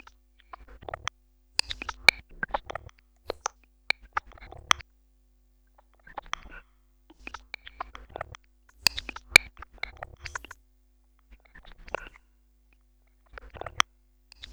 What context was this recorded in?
I would make a pattern using one track on the AR, then record it as a sample, then assign that sample to same track and repeat.